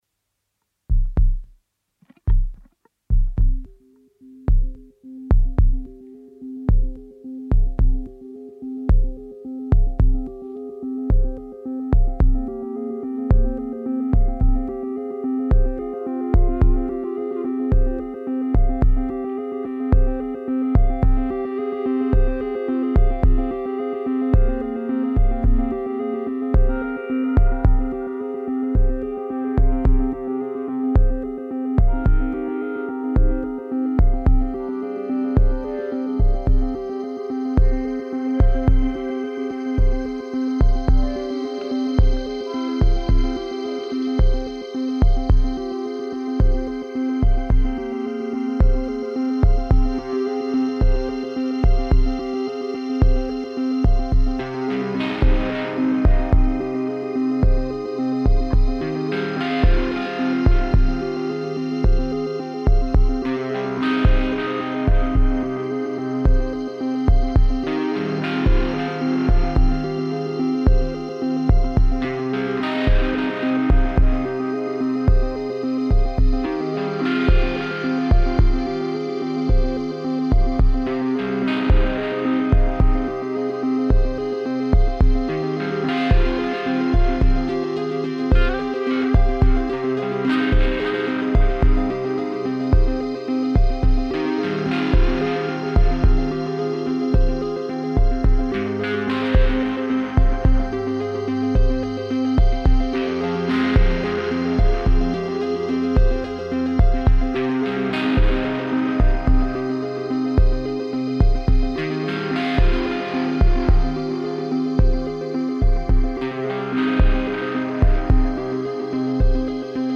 An improvisation around a sequence set up on the analogue modular system
guitar
electronic instruments
An improvisation which happened late at night during a break
A fully improvised piece, which, however, despite of some little flaws, possibly quite well shows the way him and me are playing together in certain moments – which I tend to like. The melancholic, somewhat wistful mood made me think of autumn winds when strolling through post-summer landscapes around my home, hence the title.